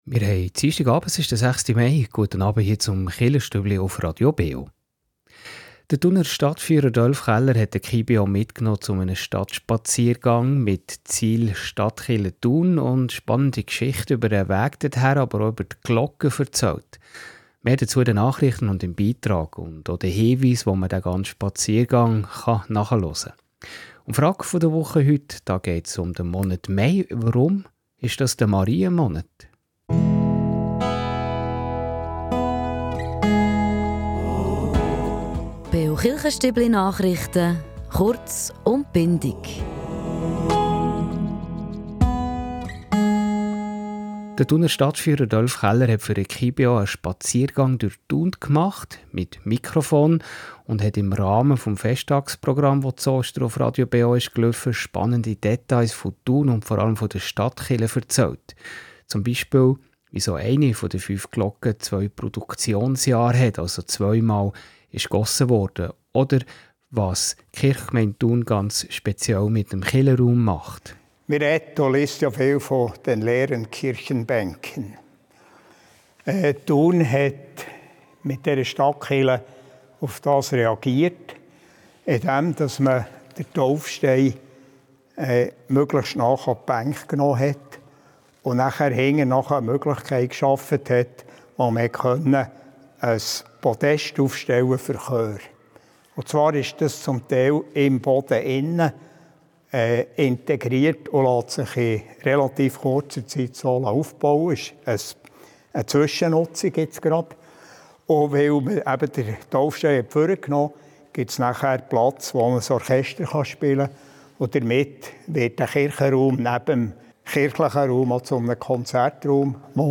Den ganzen Spaziergang kann man hier auf kibeo nachhören .Und die Frage der Woche heute: Es geht um den Monat Mai – warum wird dieser auch Marienmonat genannt?